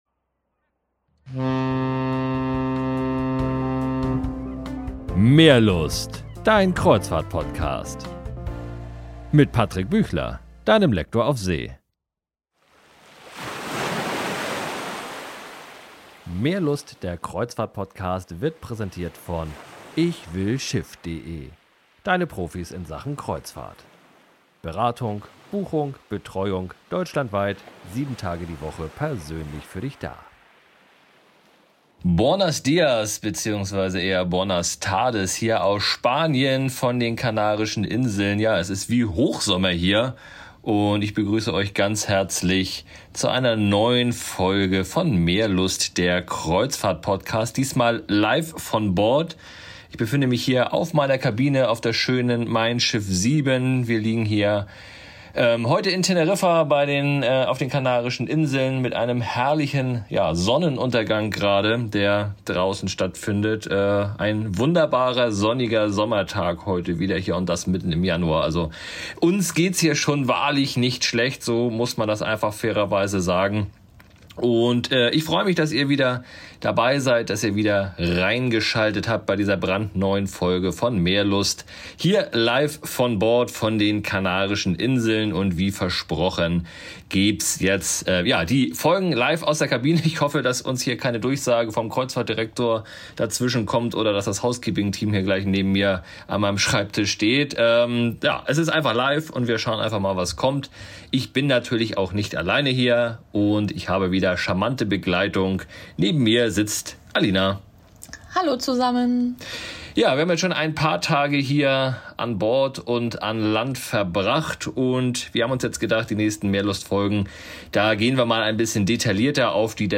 Ahoi aus der Sonne der Kanaren, Ahoi von Bord der MeinSchiff 7.